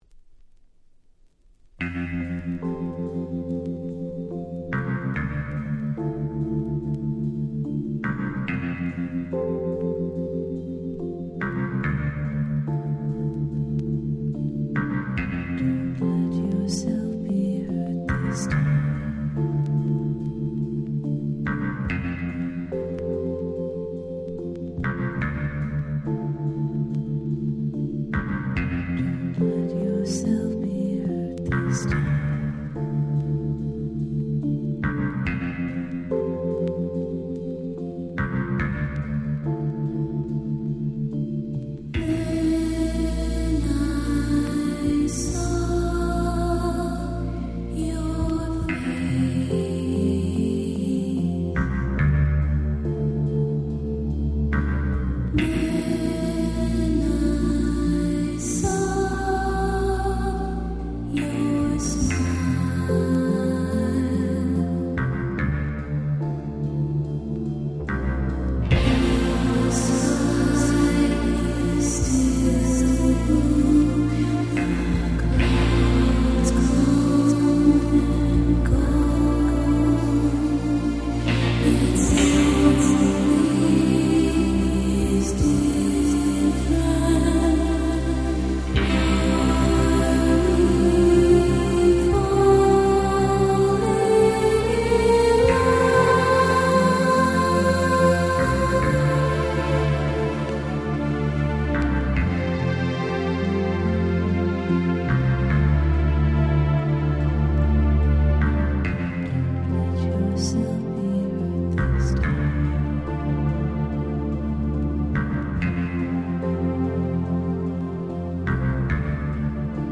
90’ Smash Hit UK Soul !!
幻想的で壮大な原曲も最高です。